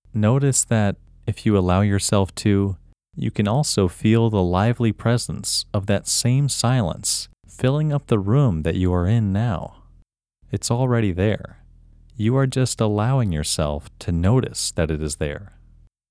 WHOLENESS English Male 7